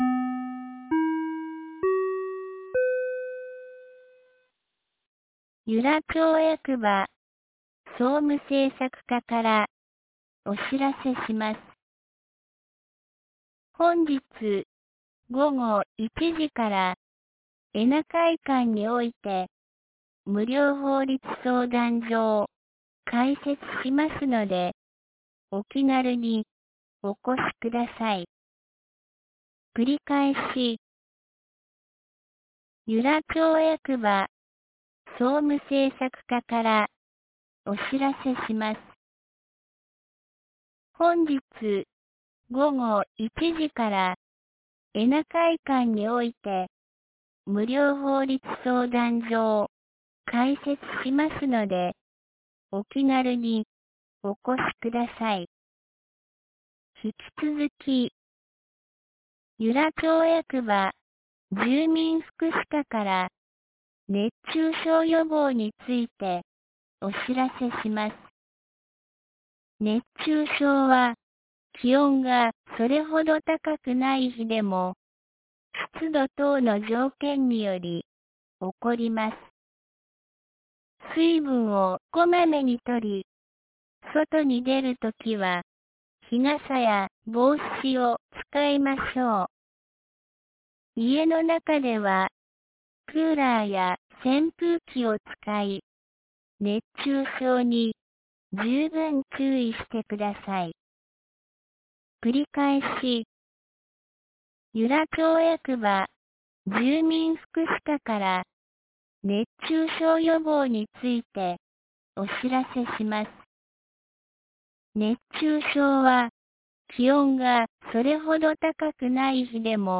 2019年08月08日 12時22分に、由良町より全地区へ放送がありました。